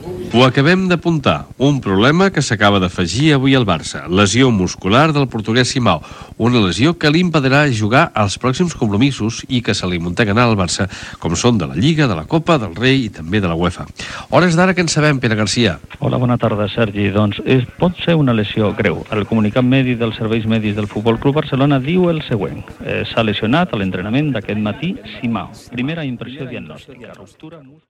49dbbff2b3813c3fbbc9fe695bdca3af60f57572.mp3 Títol Ràdio 4 Emissora Ràdio 4 Cadena RNE Titularitat Pública estatal Descripció Informació de l'entrenament del FC Barcelona, lesió de Simao. Gènere radiofònic Esportiu